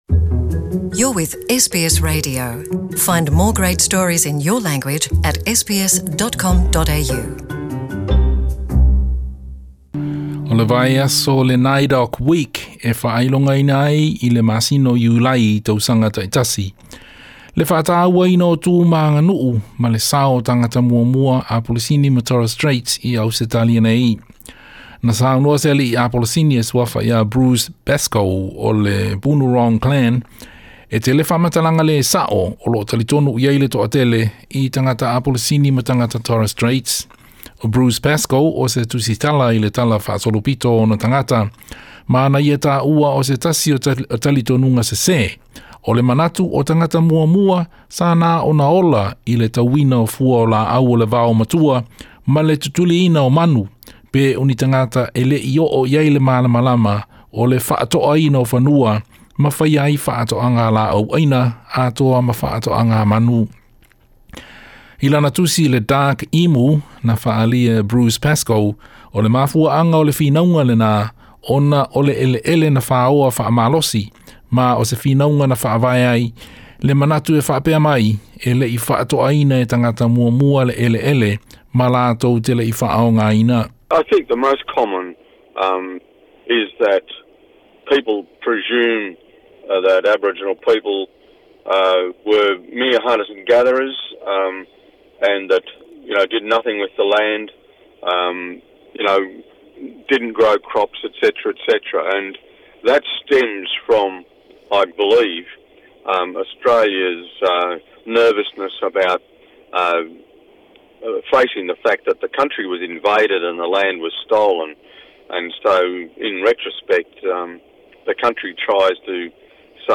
O se ripoti lenei i le alii Apolisini o Bruce Pascoe, o se tusitala faasolopito i mataupu i tagata muamua.